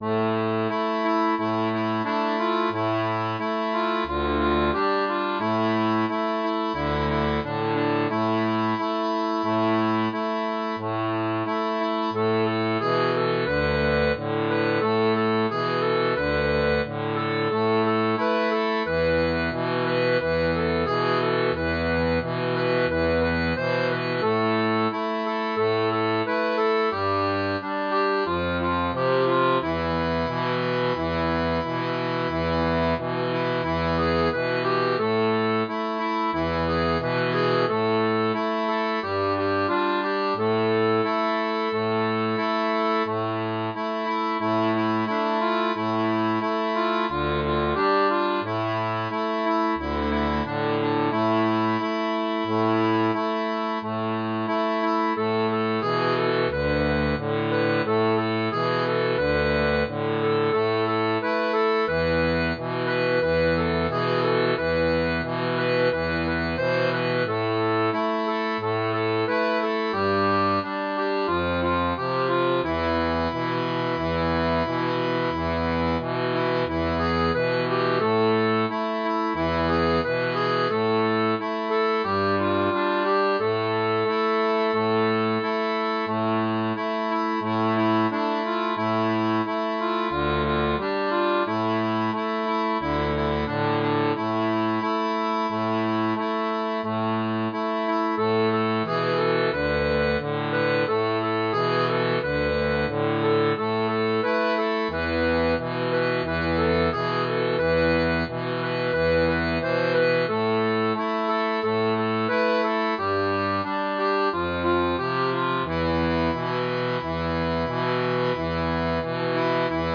• La tablature transposée en La pour diato 2 rangs